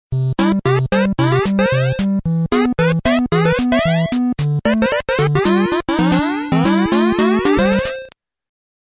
• Качество: 128, Stereo
OST